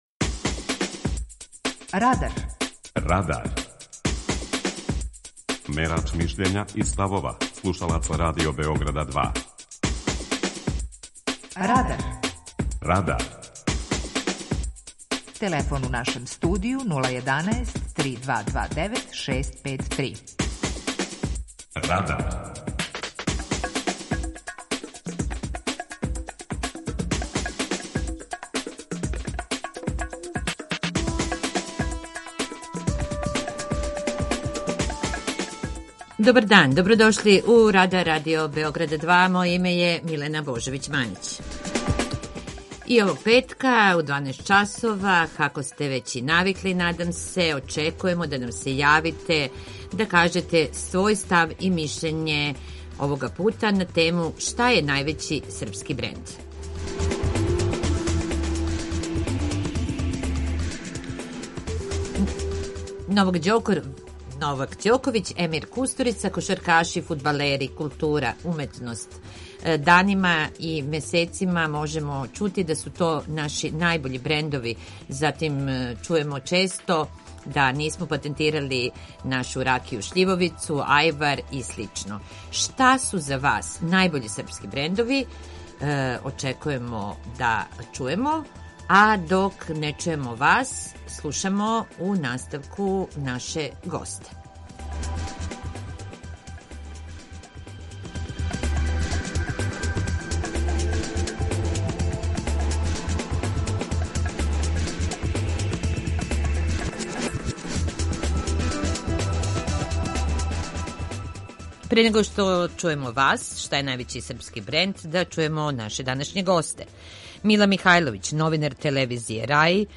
Питање Радара је: Шта је најбољи српски бренд? преузми : 18.92 MB Радар Autor: Група аутора У емисији „Радар", гости и слушаоци разговарају о актуелним темама из друштвеног и културног живота.